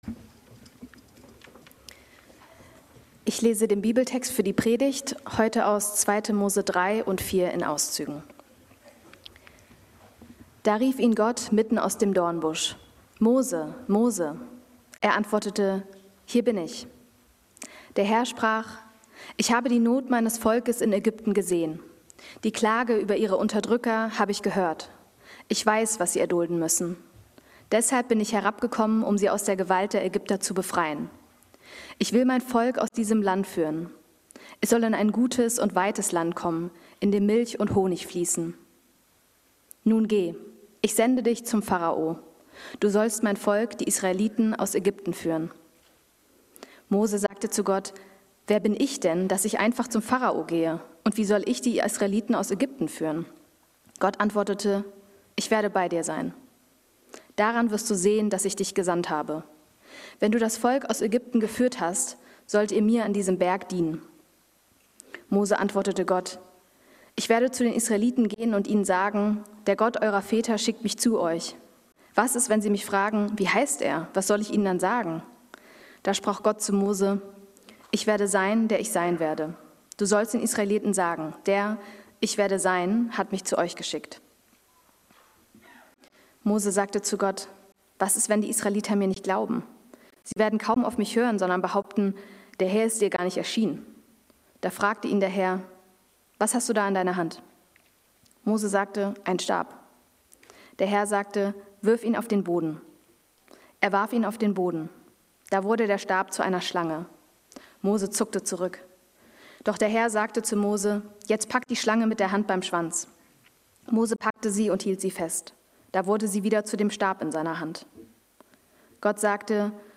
Durch wen Gott wirkt ~ Berlinprojekt Predigten Podcast
Religion & Spiritualität